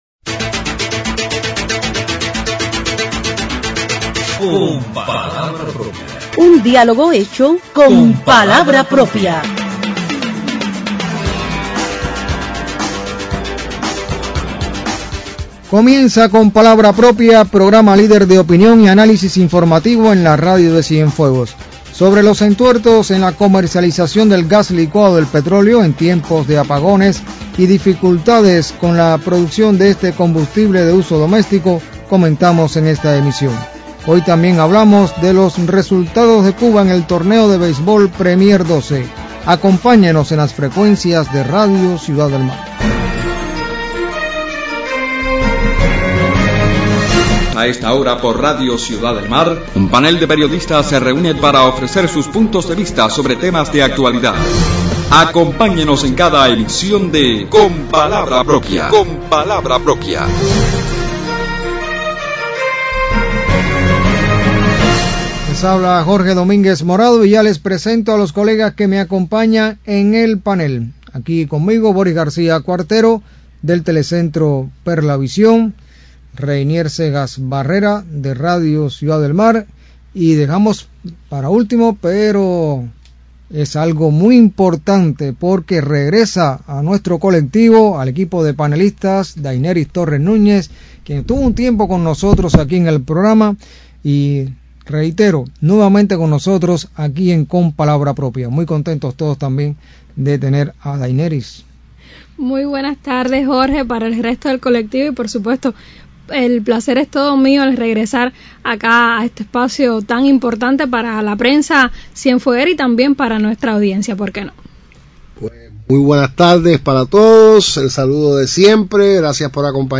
Los dilemas para lograr una efectiva comercialización del gas licuado del petróleo en Cienfuegos motivan el debate de los panelistas de Con palabra propia en la emisión del sábado 23 de noviembre.